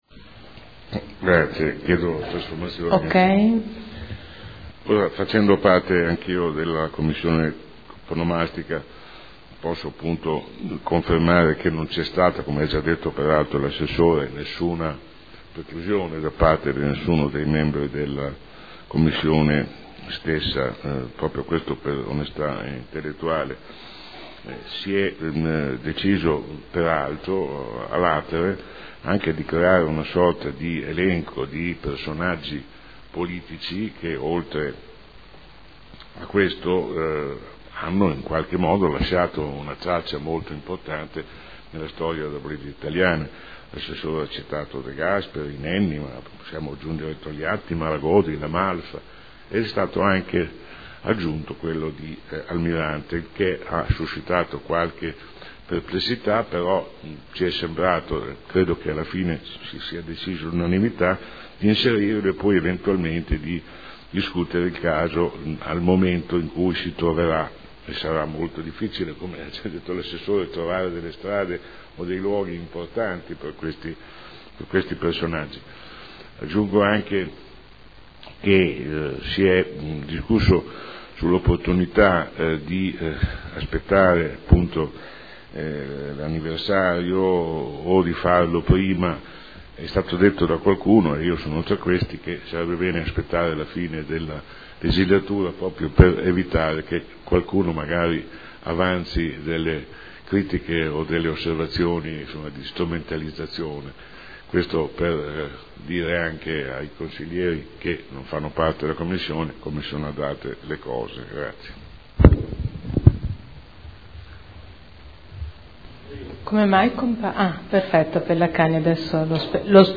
Seduta del 7 aprile. Interrogazione dei consiglieri Garagnani, Andreana, Gorrieri, Trande (P.D.) avente per oggetto: “E’ possibile onorare la memoria di Enrico Berlinguer, a quasi trent’anni dalla sua morte, prima della scadenza dell’attuale consigliatura, attribuendogli un luogo della città degno del suo spessore morale e politico?”.